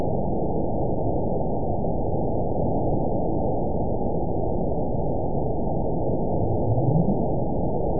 event 914250 date 05/02/22 time 04:28:26 GMT (3 years ago) score 9.57 location TSS-AB02 detected by nrw target species NRW annotations +NRW Spectrogram: Frequency (kHz) vs. Time (s) audio not available .wav